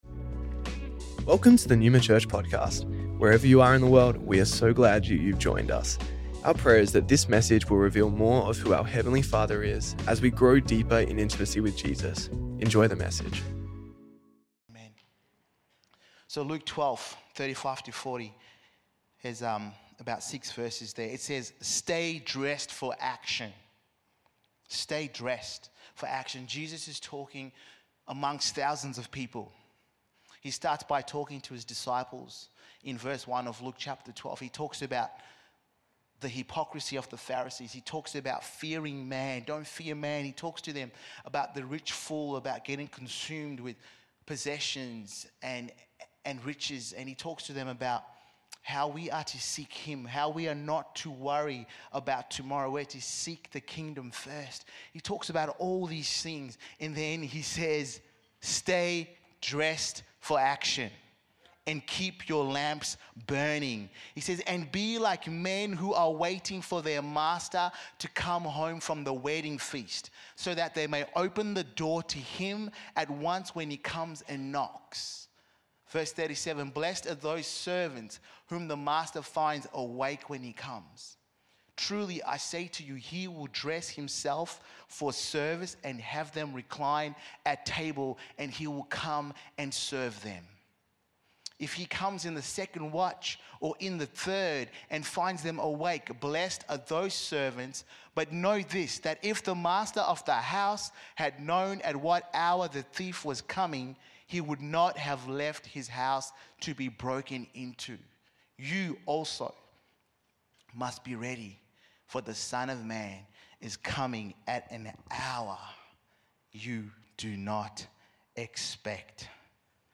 Neuma Church Melbourne South Originally recorded at the 10AM Service on Sunday 1st June 2025